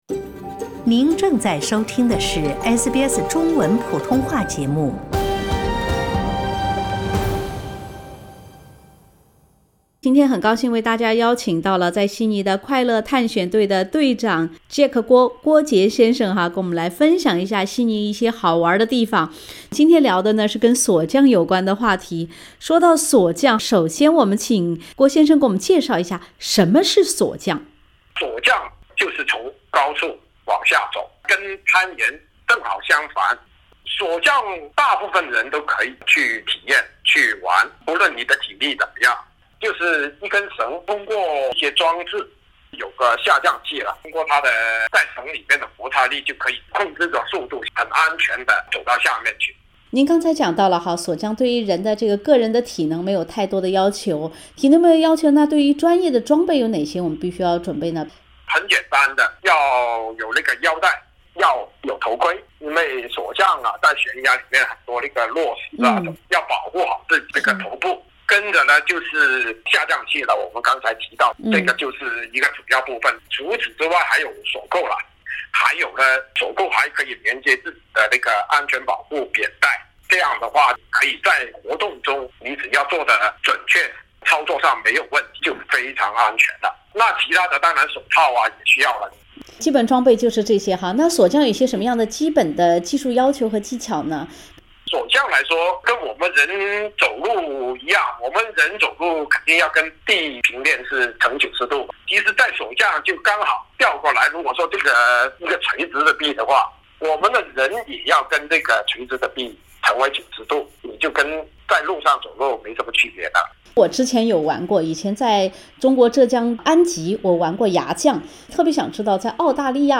SBS 普通話電台